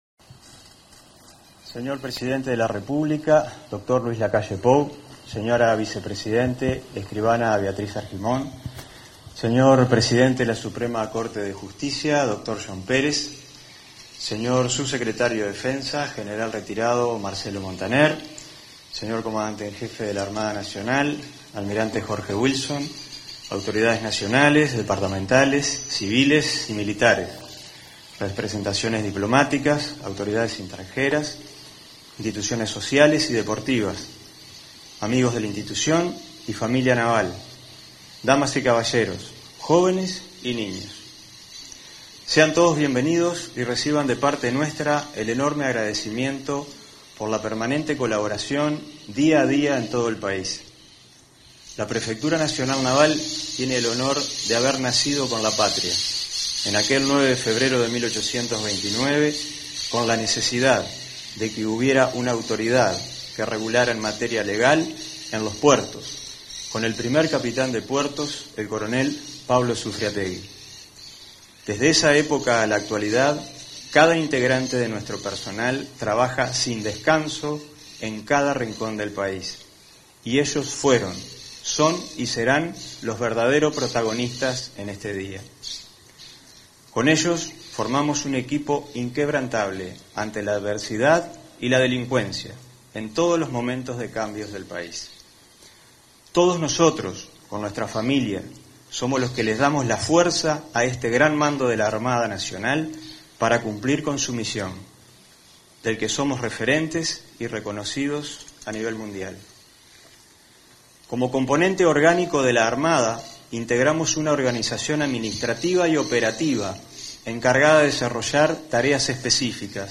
Palabras del prefecto nacional Naval, José Luis Elizondo Chiesa
Con la presencia del presidente de la República, Luis Lacalle Pou, se realizó, este 13 de febrero, el 196.° Aniversario de la Prefectura Nacional
En el evento disertó el prefecto nacional Naval, José Luis Elizondo Chiesa.